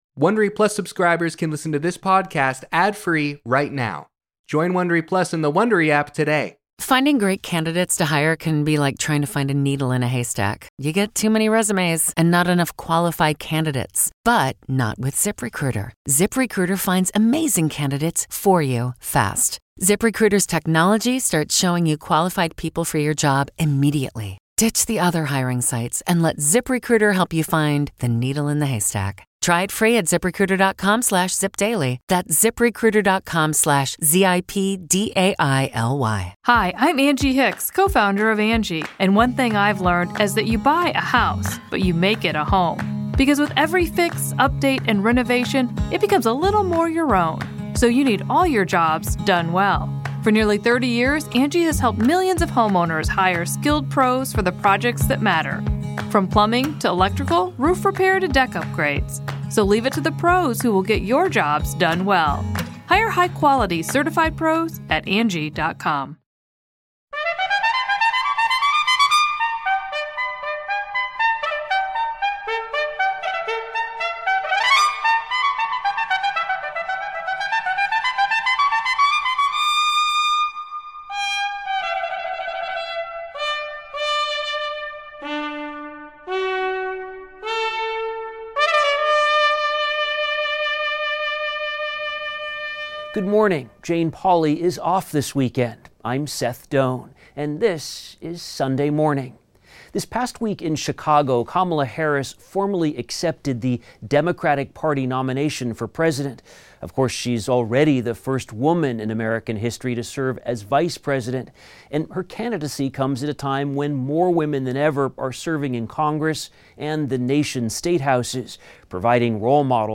Guest host: Seth Doane. In our cover story, Jane Pauley talks with some of the inspiring young women participating in Hoosier Girls State, an exercise in democracy for high school students. Also: Anthony Mason sits down with Mick Jagger, Keith Richards and Ronnie Wood of The Rolling Stones; Seth Doane reports on Opera for Peace, an initiative to help diversify opera's performers and audience;